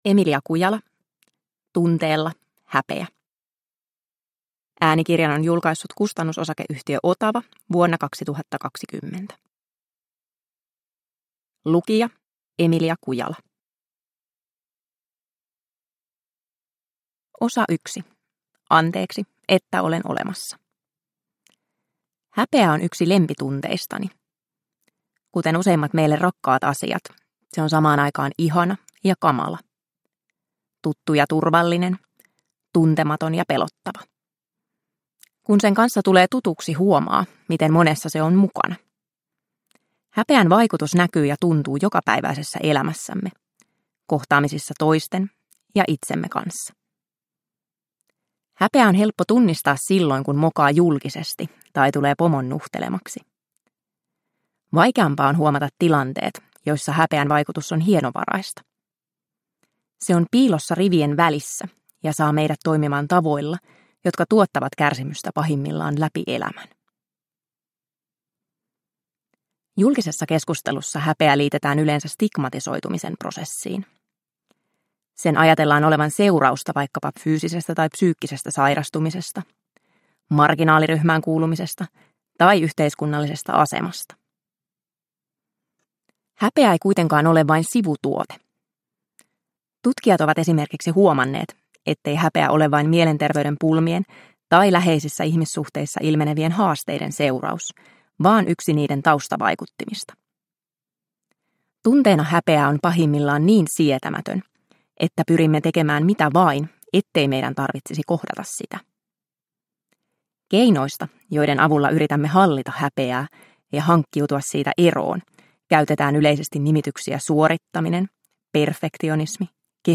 Tunteella. Häpeä – Ljudbok – Laddas ner